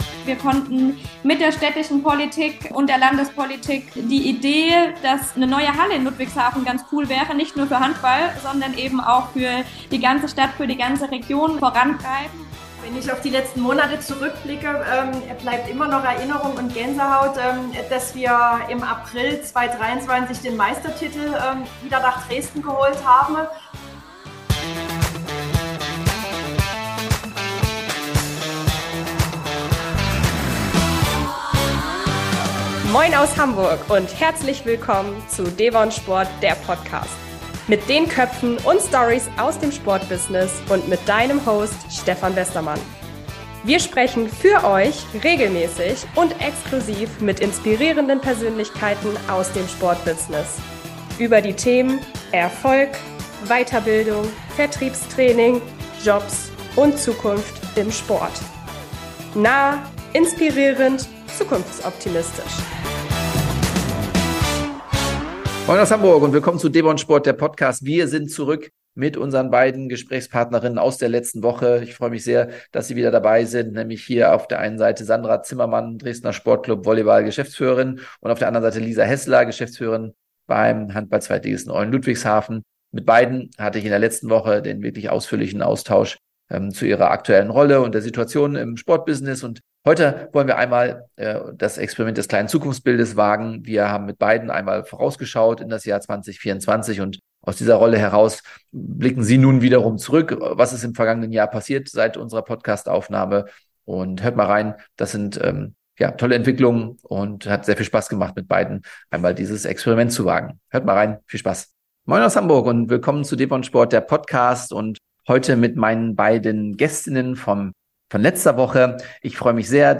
heute in Teil 2 des Interviews ihre Vision für die Zukunft in der